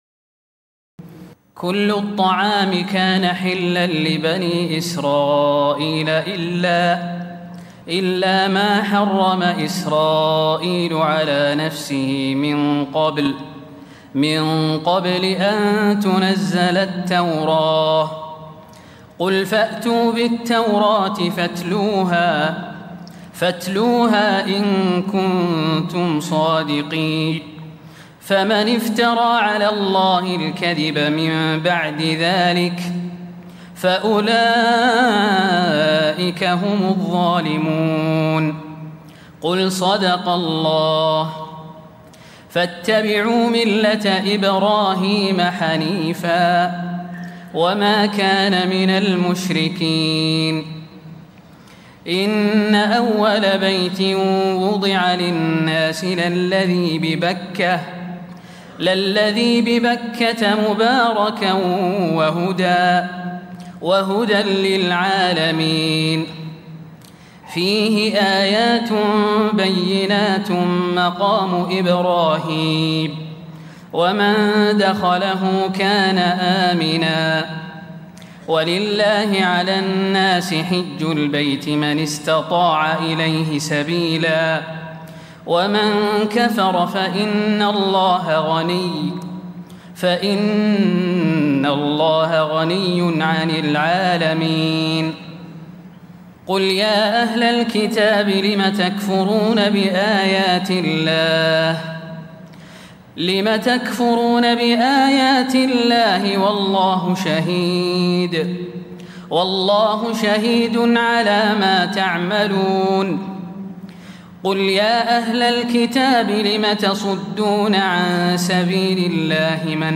تراويح الليلة الرابعة رمضان 1436هـ من سورة آل عمران (93-168) Taraweeh 4 st night Ramadan 1436H from Surah Aal-i-Imraan > تراويح الحرم النبوي عام 1436 🕌 > التراويح - تلاوات الحرمين